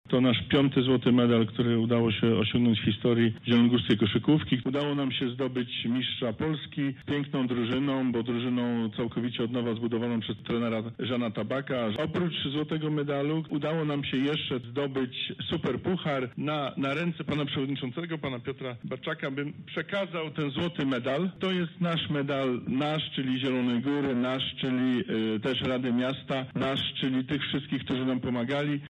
Przewodniczący rady miasta Piotr Barczak w imieniu radnych złożył podziękowania